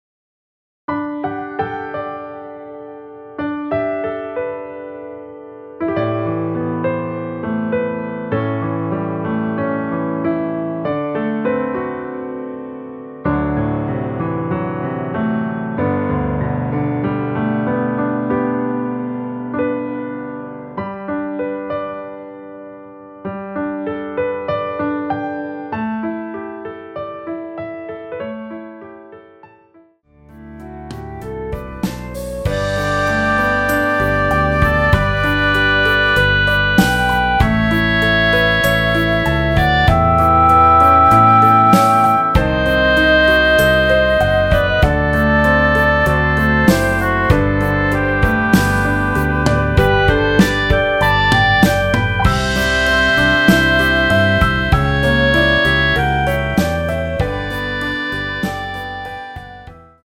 앞부분30초, 뒷부분30초씩 편집해서 올려 드리고 있습니다.
중간에 음이 끈어지고 다시 나오는 이유는
위처럼 미리듣기를 만들어서 그렇습니다.